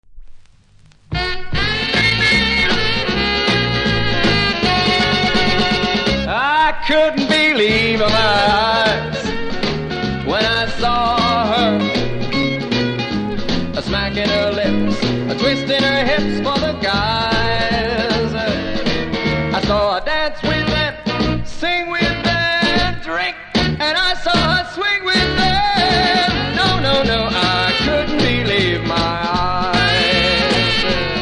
熱変形により僅かにノイズ拾いますがプレイは問題無いレベル。